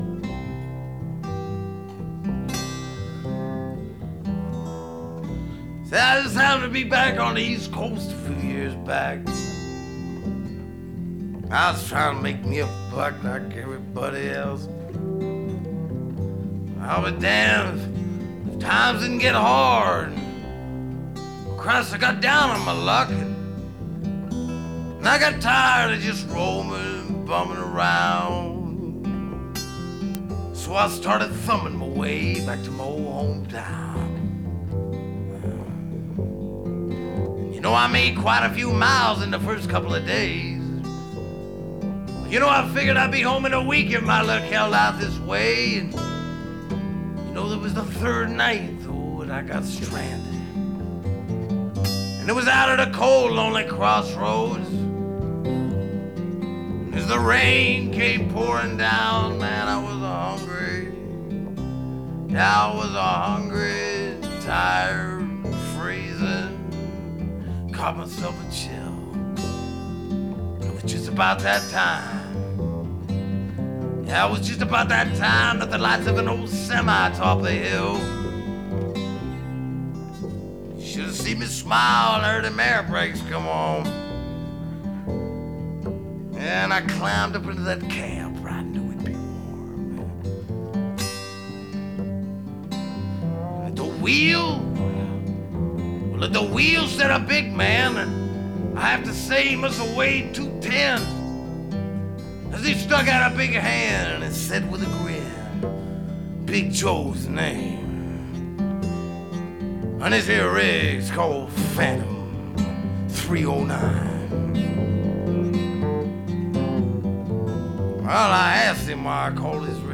Melancholic comfy.